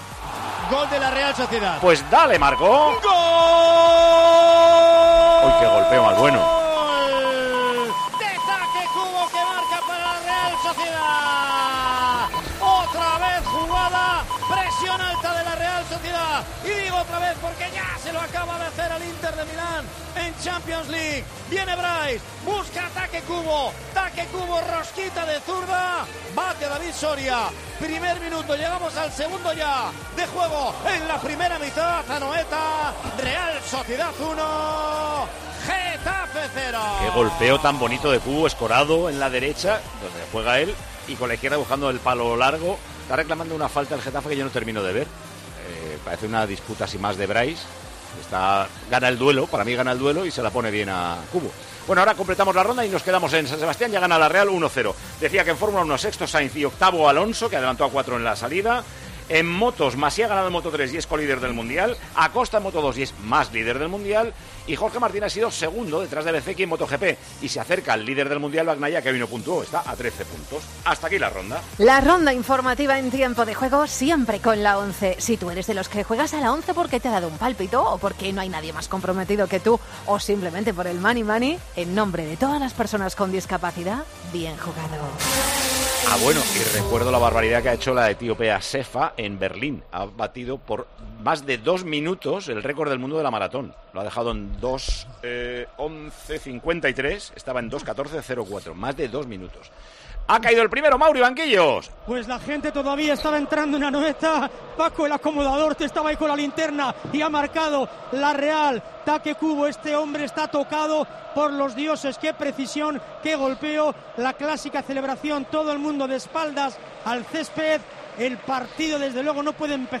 Micrófono de COPE en Anoeta Reale Arena